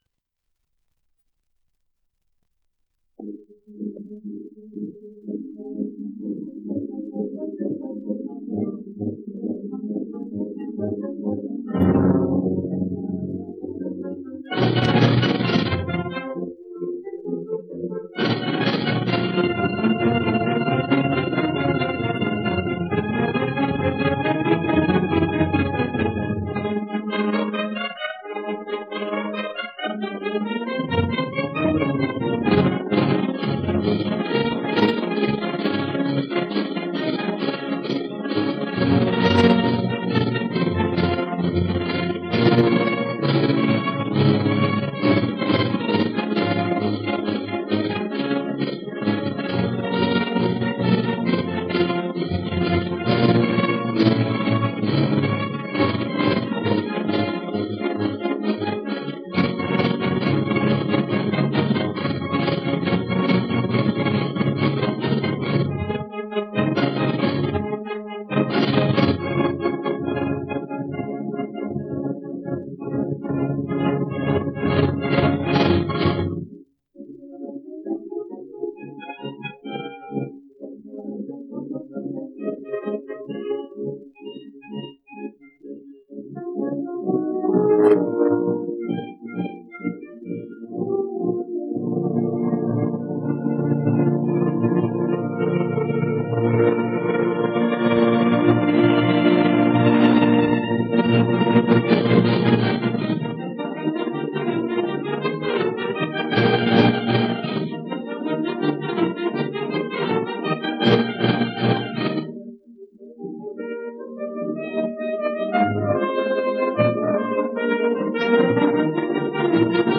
Pan y toros: Pasacalle y Final (sonido remasterizado)